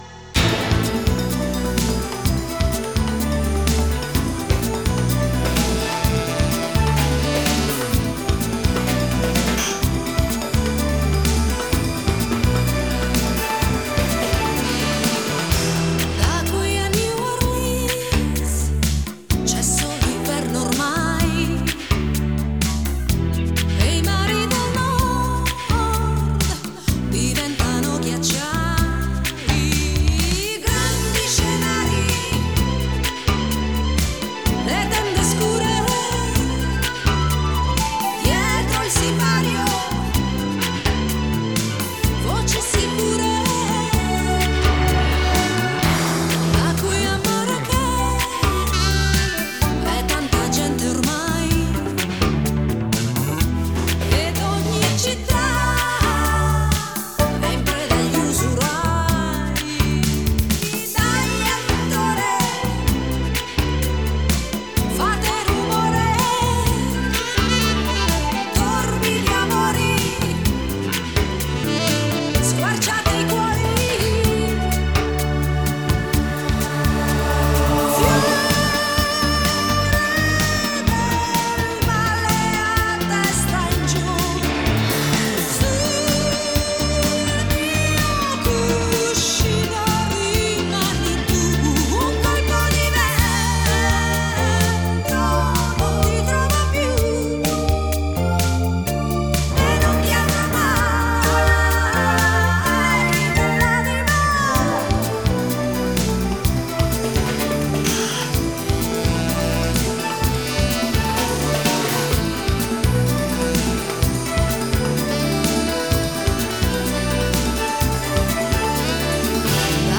Жанр: Pop, Europop,Vocal,Shlager